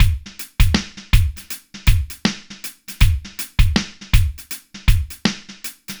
Index of /90_sSampleCDs/AKAI S6000 CD-ROM - Volume 4/Others-Loop/BPM_80_Others1